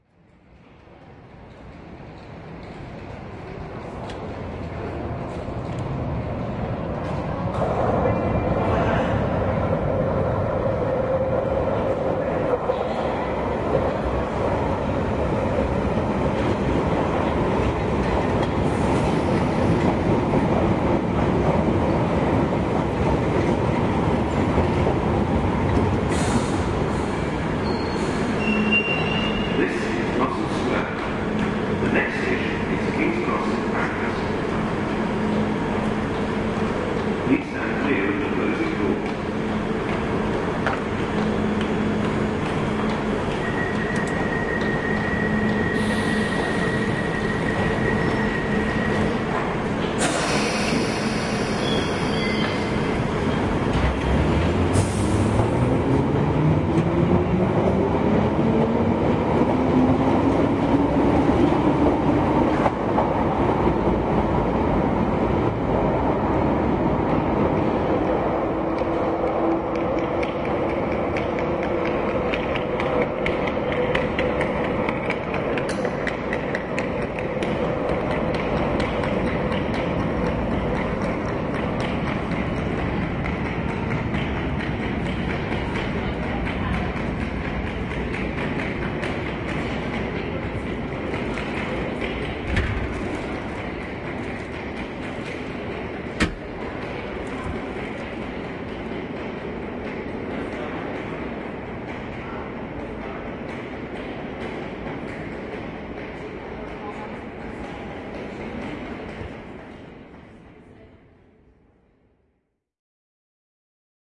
描述：地铁站的总体氛围。一列火车到达和离开，可以听到男性播音员的声音，以及站台上的乘客和脚步声。录制于伦敦地铁的国王十字站，
标签： 伦敦地下 语音 地下 现场记录 伦敦 公告
声道立体声